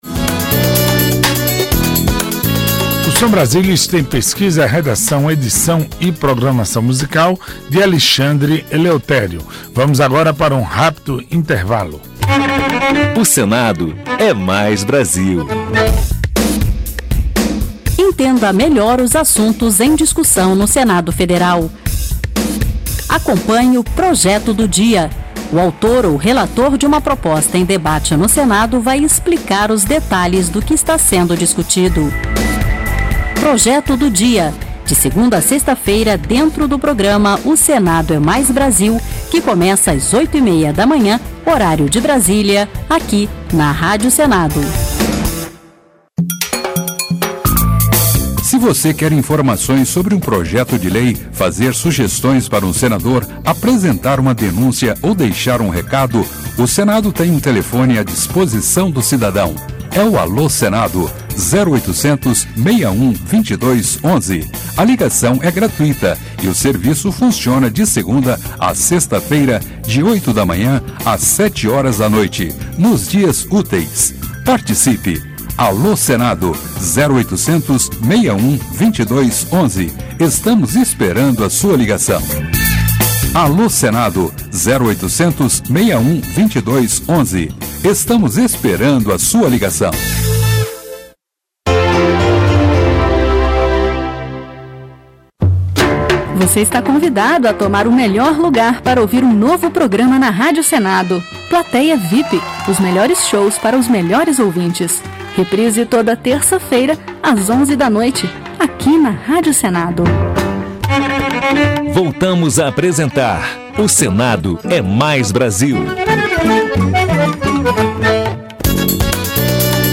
Notícias: giro pelos estados Projeto do Dia: Jayme Campos (DEM-MT) fala sobre projeto aprovado pelo Senado que cria Zona de Processamento de Exportação em Rondonópolis.